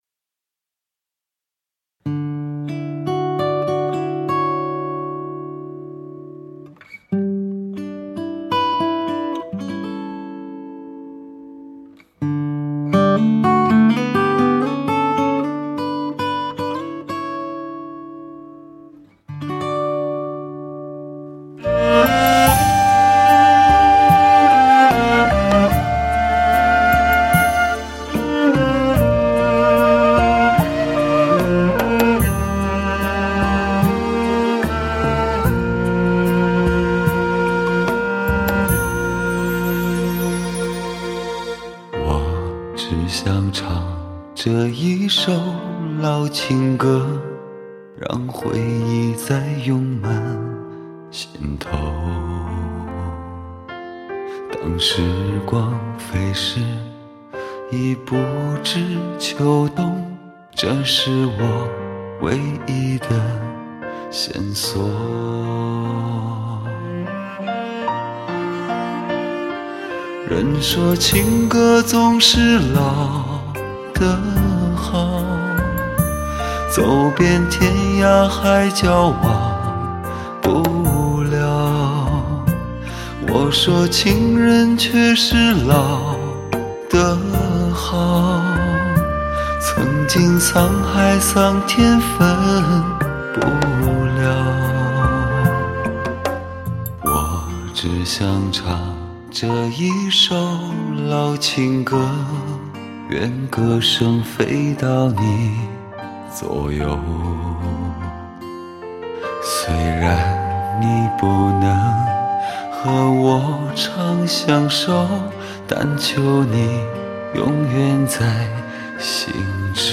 20HZ超级低频胸腔共振，100DB超宽阔动态范围，掀起试听风暴，引领HI-FI潮流。